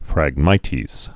(frăg-mītēz)